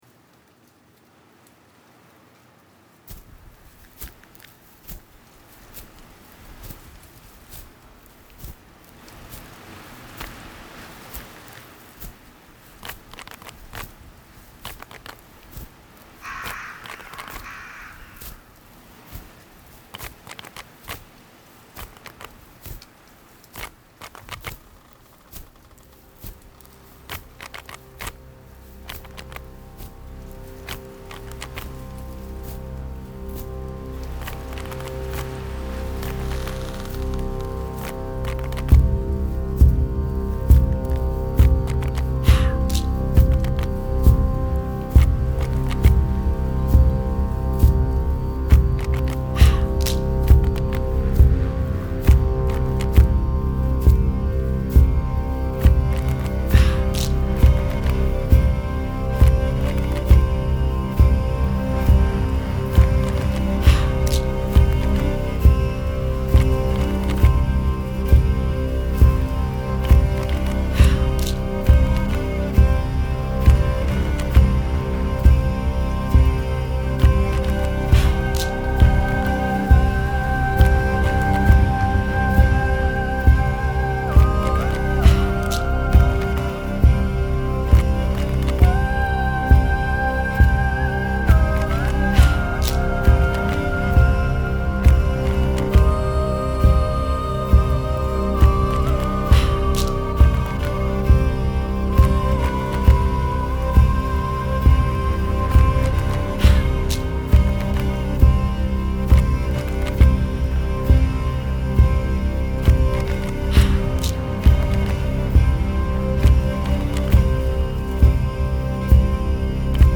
Dark and foreboding?
Strong elements of traditional folk?